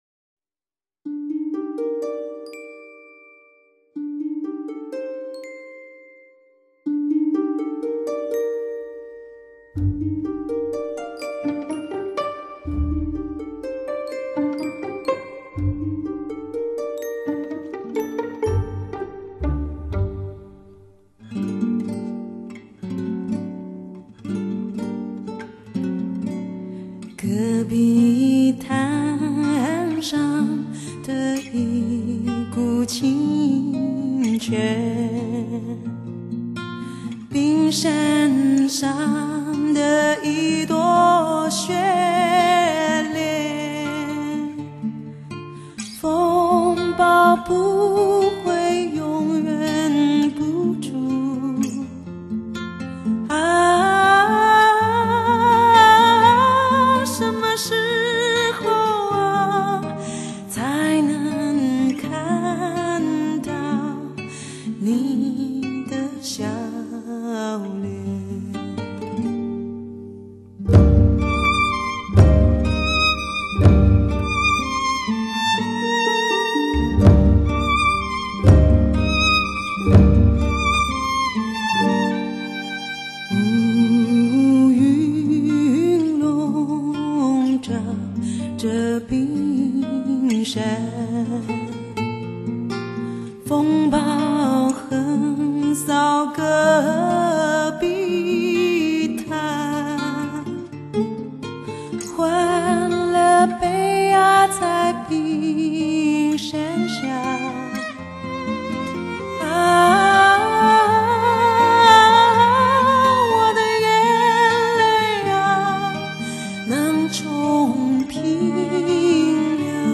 ----岁月悠悠,歌悠悠,也许只能透过她那自然贴心,还略带着豪迈的歌声,才可以唤醒我们对流逝岁月的记忆和赞美.